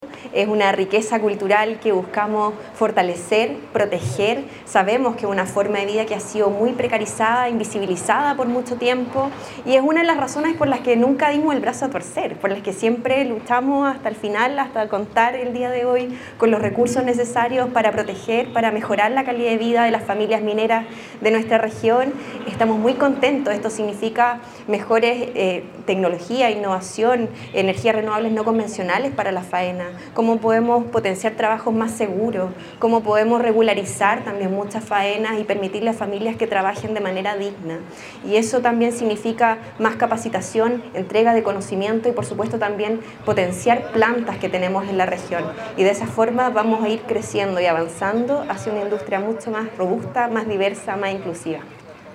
Por su parte, la Seremi de Minería, Constanza Espinosa, enfatizó en los avances que traerá este convenio para los trabajadores del sector.
SEREMI-DE-MINERIA-CONSTANZA-ESPINOZA.mp3